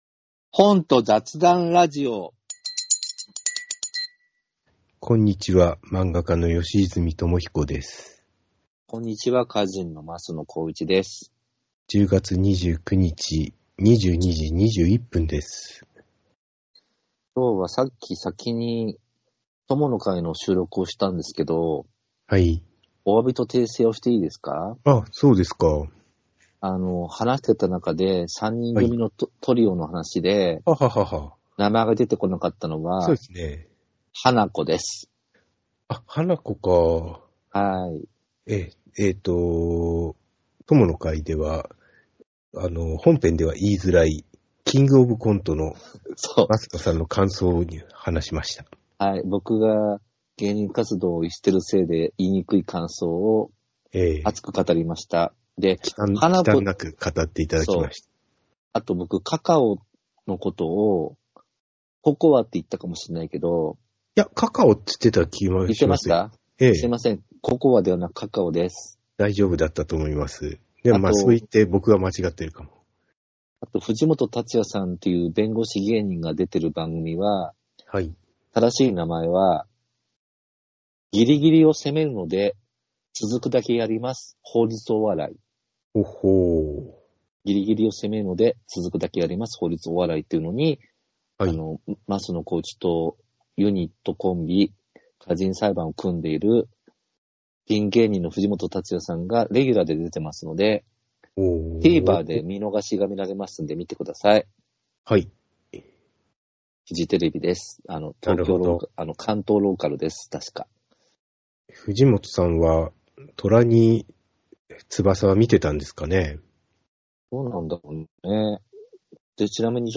漫画家の古泉智浩と歌人の枡野浩一が、本と雑談のラジオをお送りします。課題図書や近況などお話します。